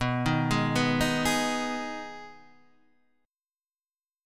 Abm/Cb Chord